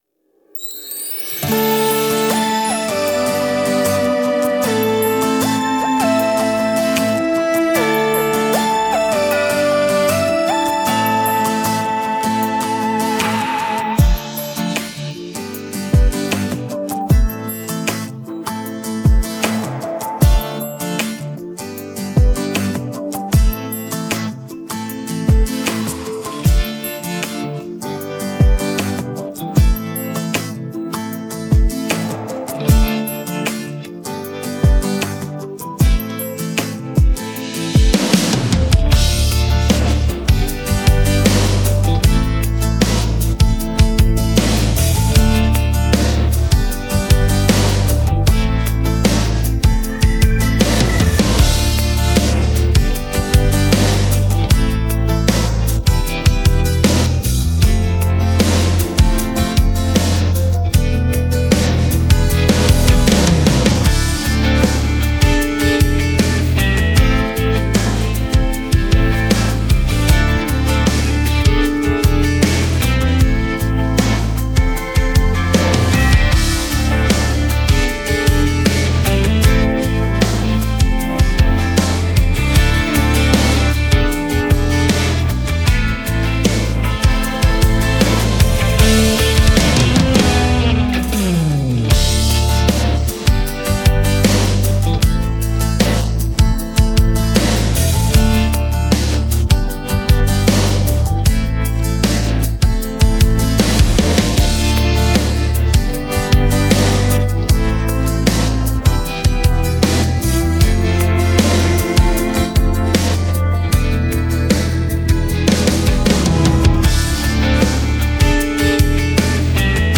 Современные песни про Россию для детей 🎶